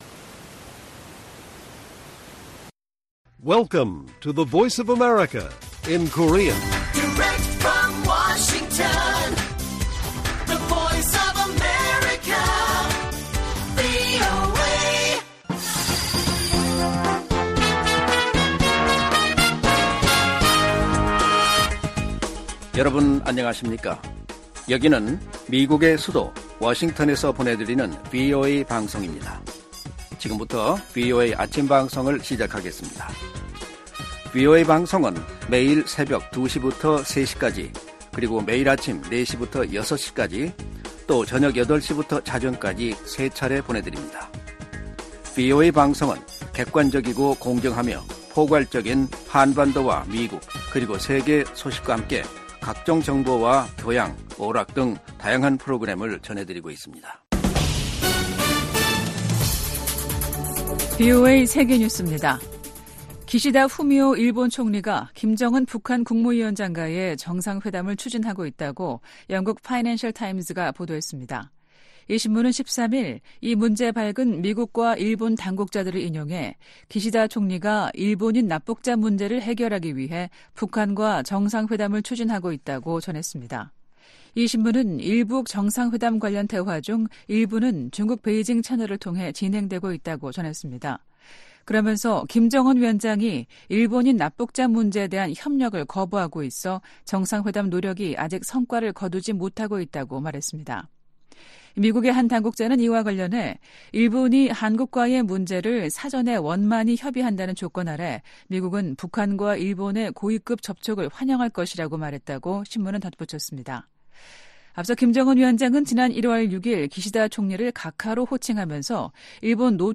세계 뉴스와 함께 미국의 모든 것을 소개하는 '생방송 여기는 워싱턴입니다', 2024년 2월 14일 아침 방송입니다. '지구촌 오늘'에서는 도널드 트럼프 전 미국 대통령의 북대서양조약기구(NATO·나토) 관련 발언이 논란인 가운데 독· 프·폴란드 정상이 유럽 안보를 강조한 소식 전해드리고, '아메리카 나우'에서는 트럼프 전 대통령이 2020년 대선 결과 뒤집기 시도 혐의는 면책특권 대상이 아니라는 판단에 불복해 대법원에 가져간 이야기 살펴보겠습니다.